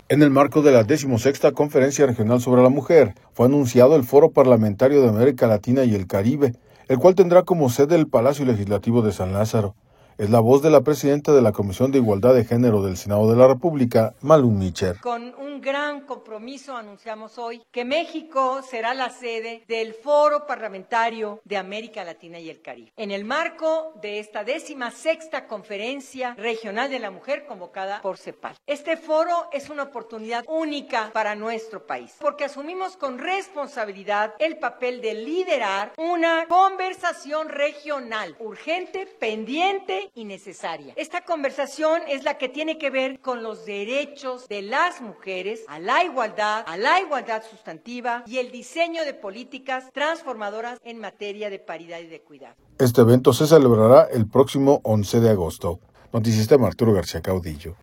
audio En el marco de la XVI Conferencia Regional sobre la Mujer, fue anunciado el Foro Parlamentario de América Latina y el Caribe, el cual tendrá como sede el Palacio Legislativo de San Lázaro. Es la voz de la presidenta de la Comisión de Igualdad de Género del Senado de la República, Malú Micher.